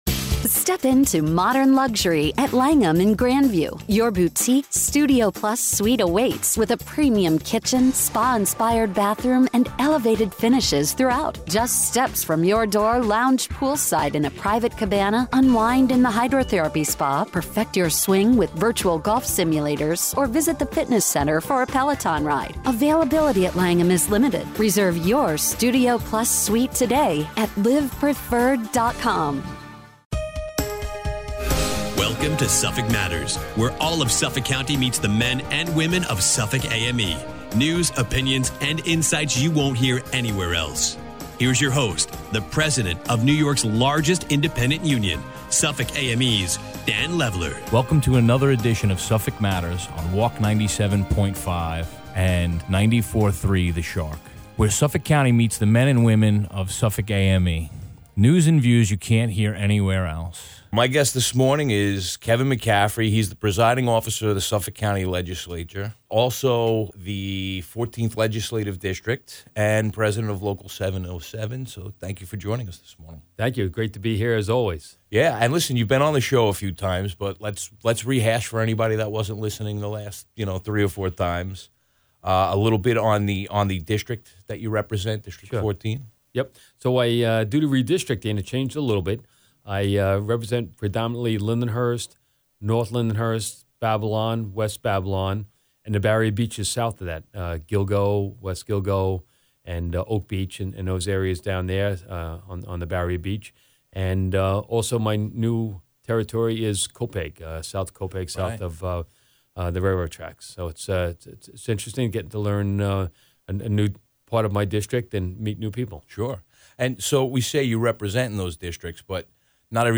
speaks with Presiding Officer of Suffolk County Legislature Kevin McCaffrey